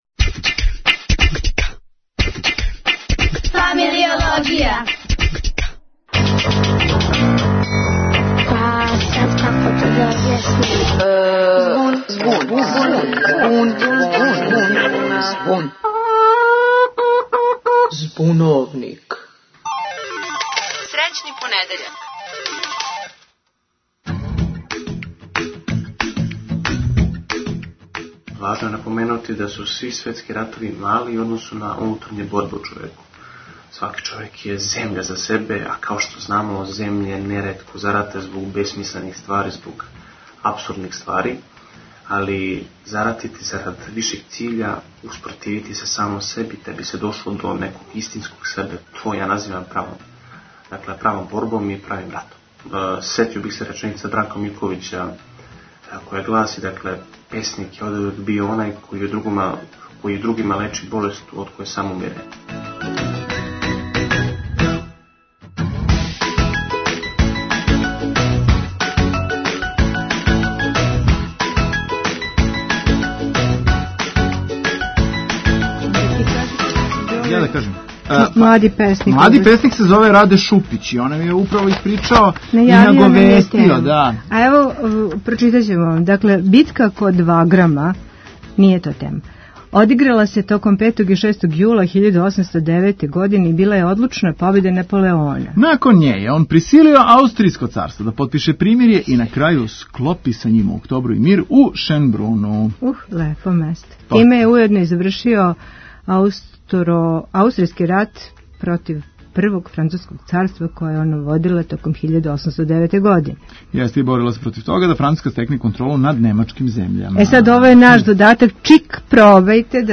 Због чега постоје ратови? Као и увек, питали смо најмлађе, младе - они ће нам говорити о својим малим и великим биткама и ратовањима у животу.